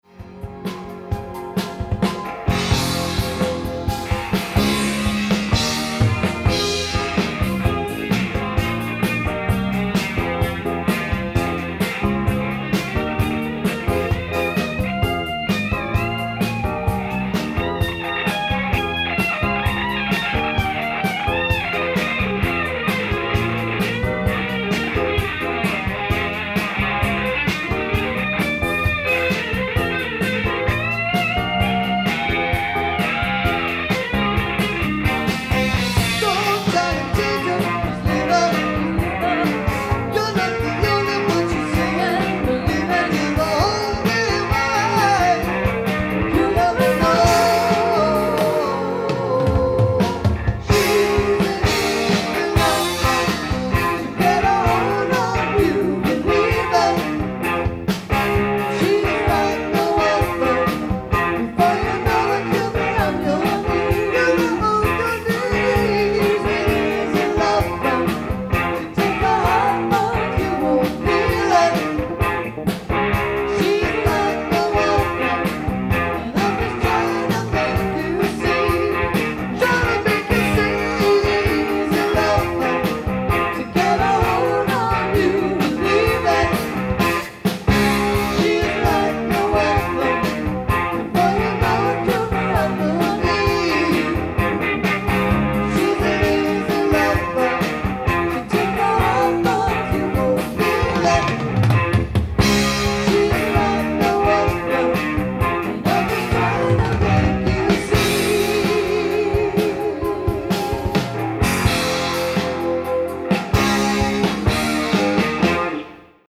Ithaca Holiday Inn December 21, 1985